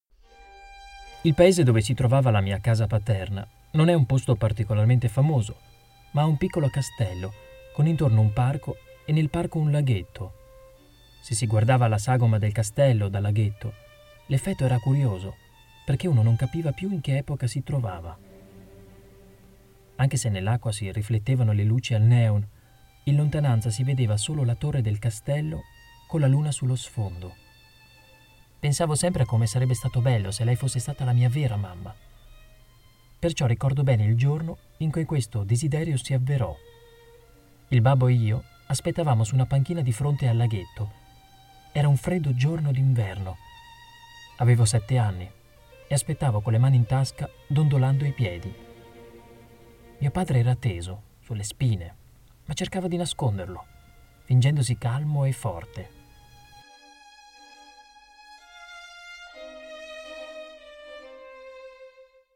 smooth, enthusiastic and warm with his Italian mother tongue accent
Sprechprobe: Sonstiges (Muttersprache):
Italien voice over artist, his vocal range is perfectly suited for corporate narration, IVR and commercials in several other languages, including English, German and Spanish.